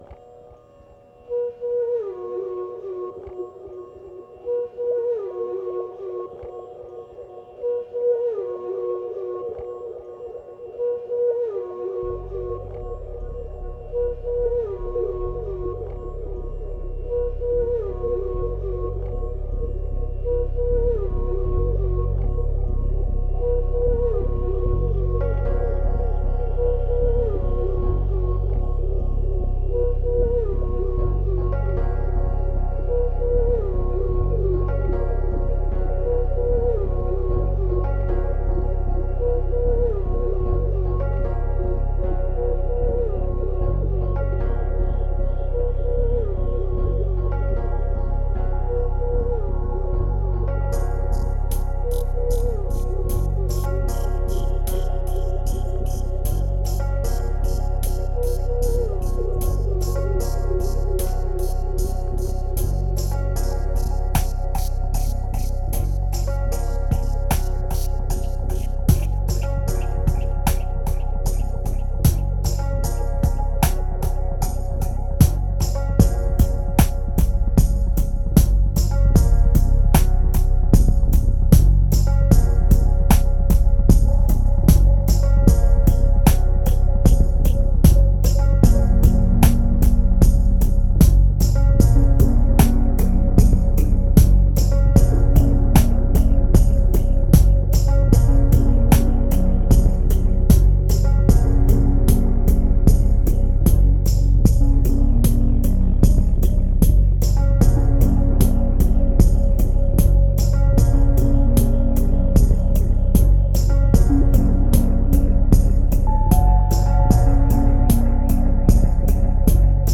2396📈 - 38%🤔 - 76BPM🔊 - 2011-01-06📅 - -23🌟